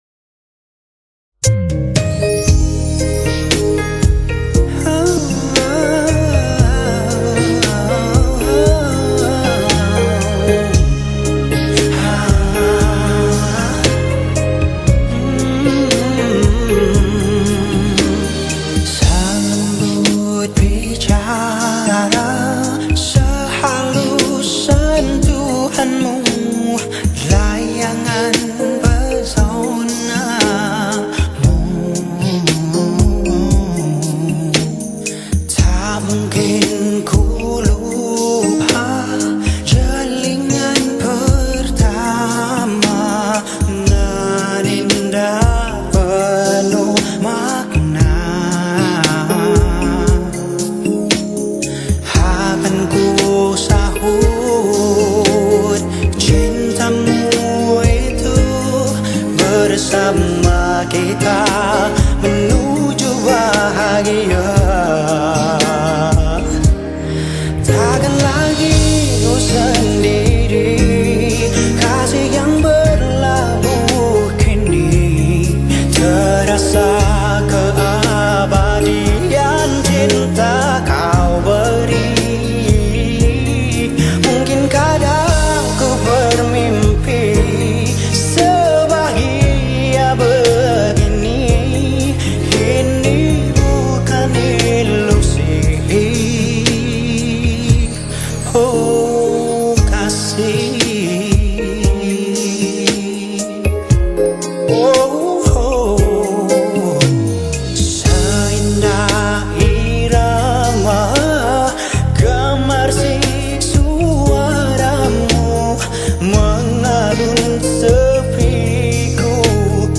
, Skor Angklung , Skor muzik